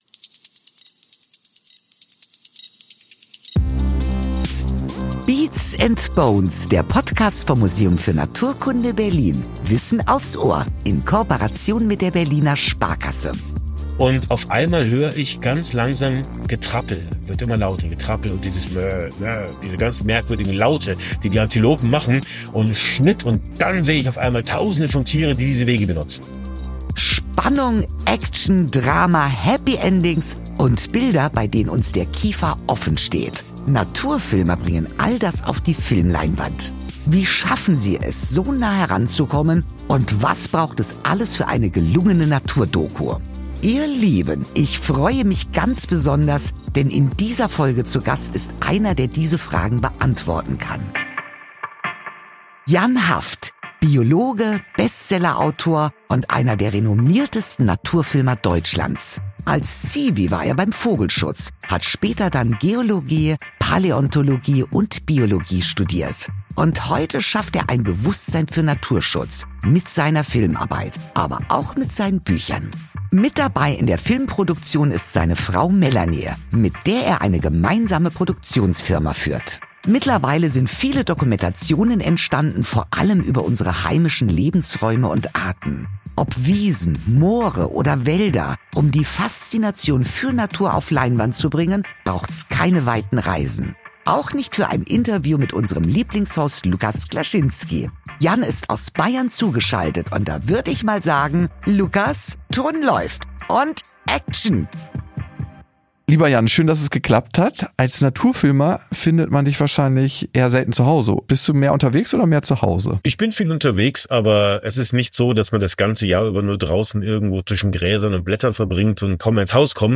mit dem Biologen und preisgekrönten Naturfilmer über seinen Traumberuf. Jan Haft erzählt, wie viel Geduld, Glück und manchmal auch Technik nötig sind, um seine tierischen und pflanzlichen Protagonisten vor die Linse zu bekommen.